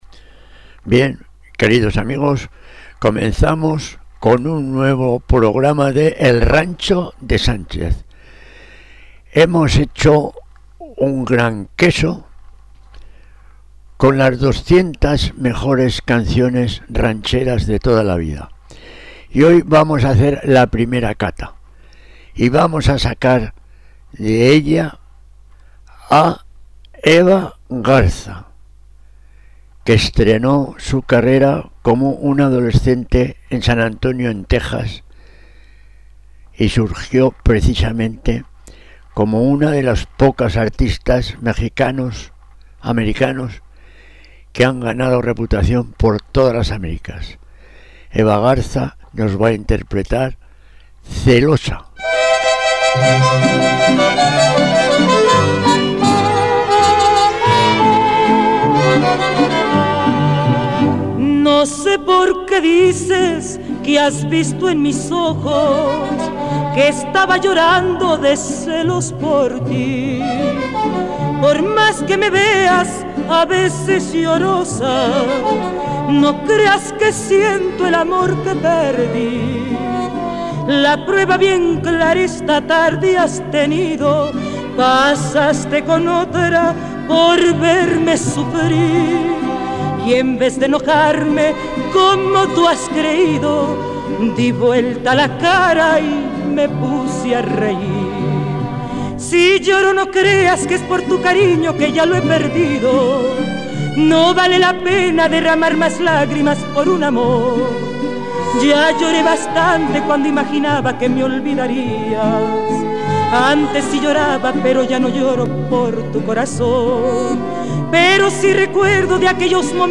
rancheras